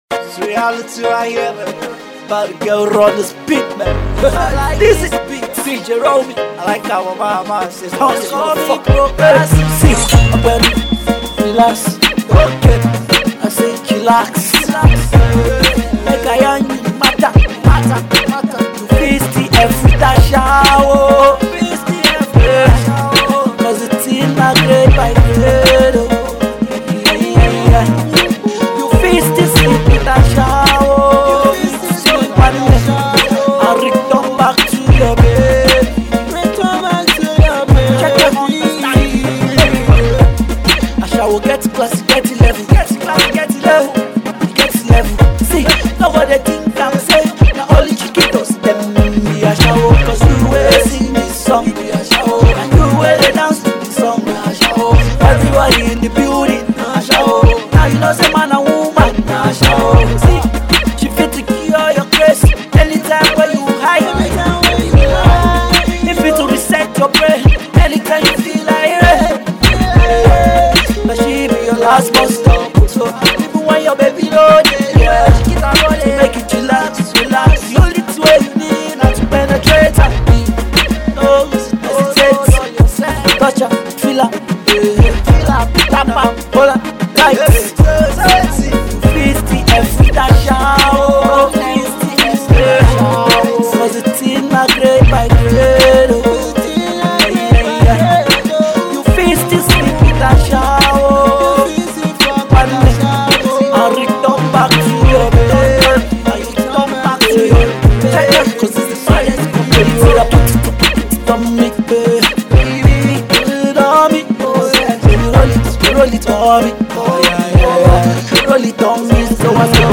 Its a catchy track